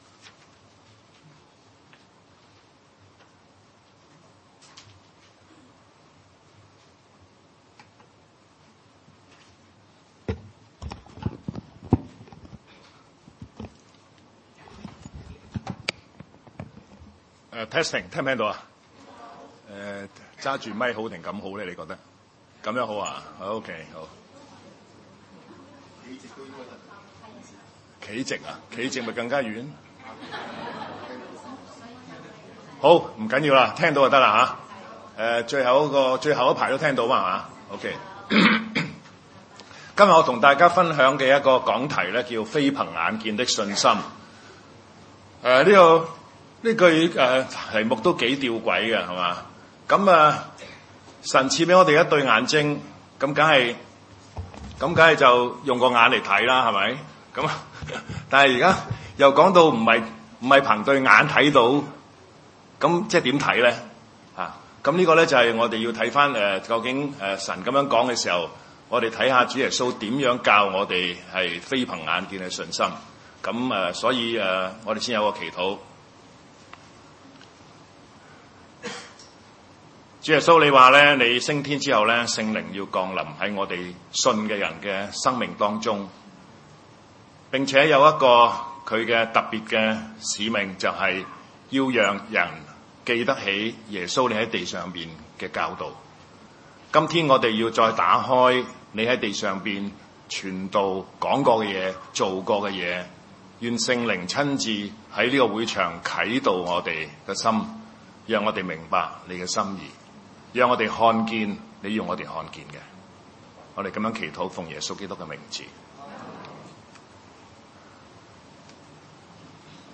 路加福音4:18-19，以賽亞書61:1 崇拜類別: 主日午堂崇拜 路加福音4:18-19 18.「主的靈在我身上，因為祂用膏膏我。